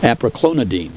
Pronunciation
(a pra KLOE ni deen)